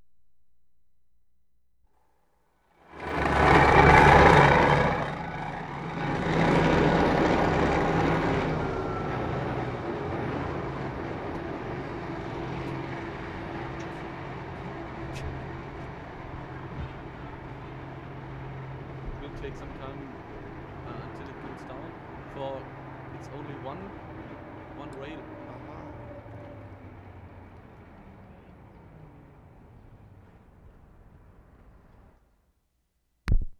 Stuttgart, Germany Feb. 27/75
( false start )
9.  COG RAILWAY, Alte Weinsteige